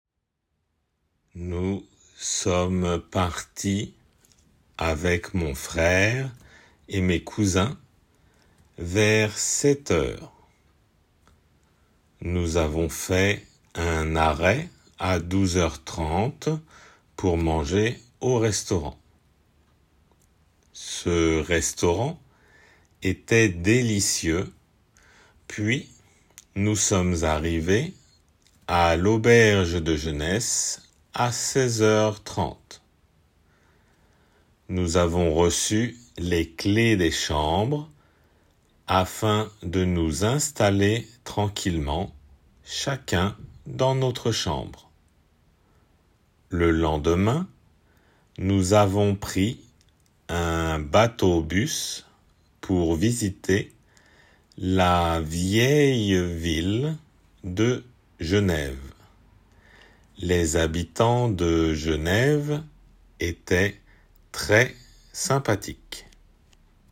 書き取り　－デイクテ
自然の速さと
書きとり用の速さと　読みます。